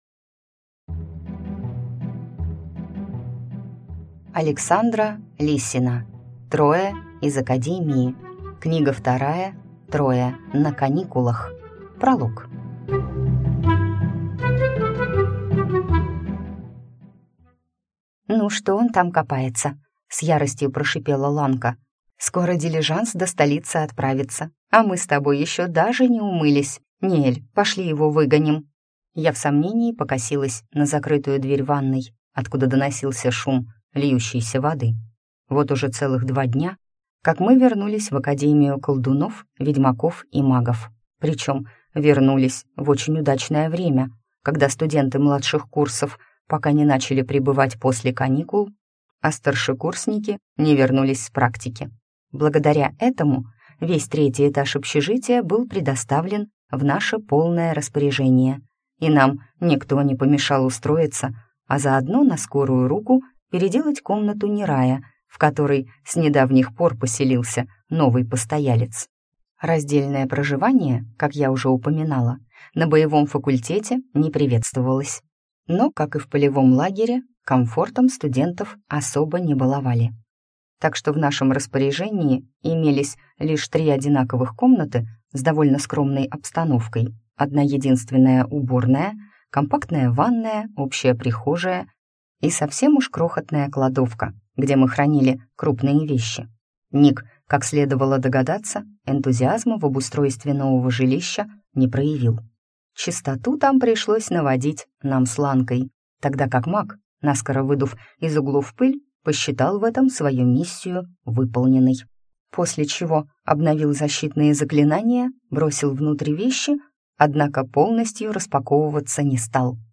Аудиокнига Трое на каникулах | Библиотека аудиокниг